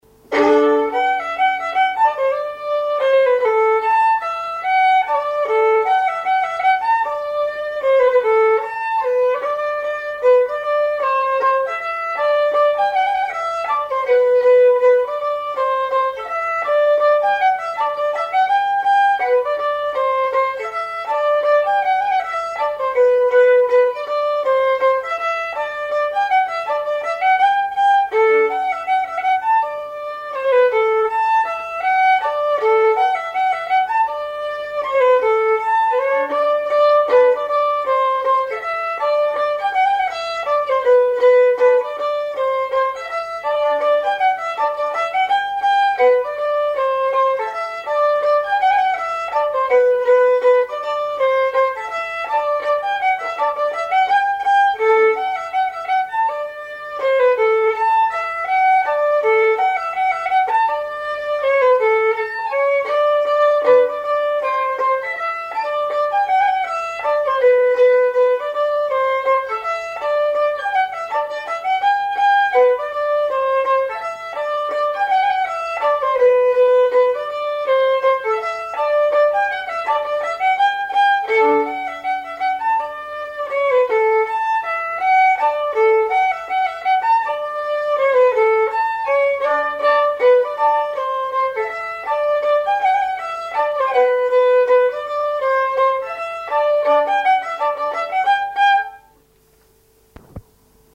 danse : mazurka
Auto-enregistrement
Pièce musicale inédite